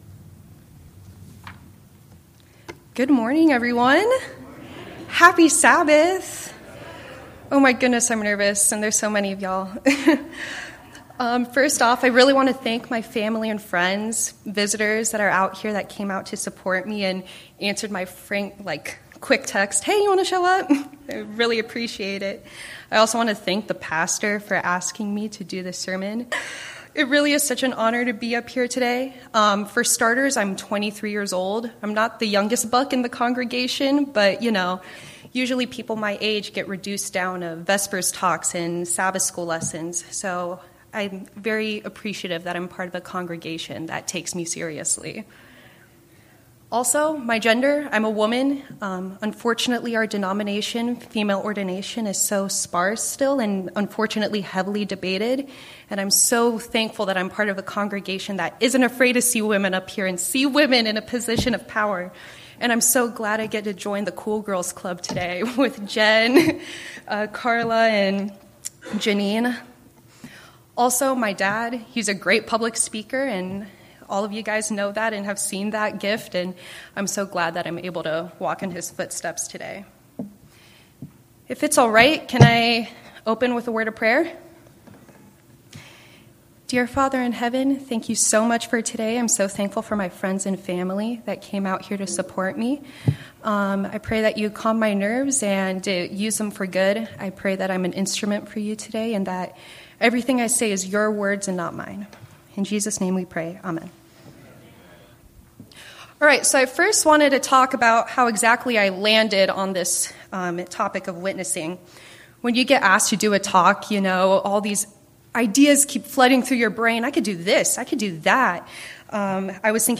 Passage: Colossians 3:14-16 Service Type: Worship Service